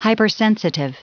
Prononciation du mot hypersensitive en anglais (fichier audio)
Prononciation du mot : hypersensitive